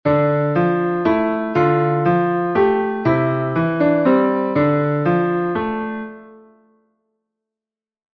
Scarborough Fare Melodie und Akkorde auf dem Piano
Scarborough Fare Akkorde mit alternativer Melodie
Doch weil die Akkorde und die Rhythmik gleich sind, klingt diese Version dem Original gar nicht so fremd.